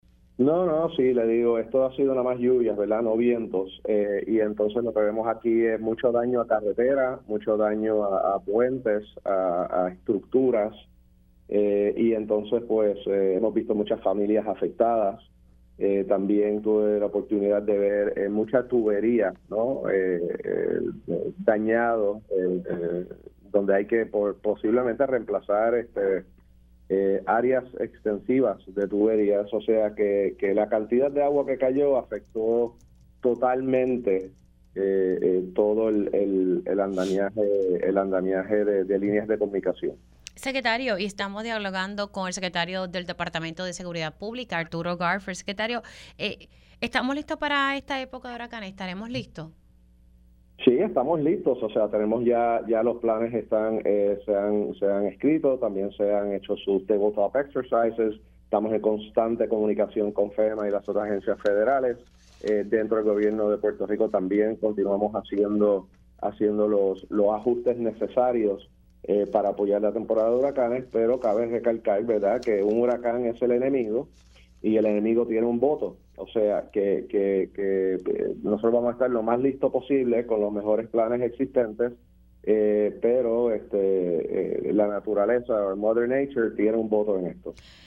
El secretario de Seguridad Pública, General Arturo Garffer indicó en Pega’os en la Mañana que podría aumentar a unos 14 municipios con daños provocados por las lluvias torrenciales que han afectado a la isla desde el 19 de abril.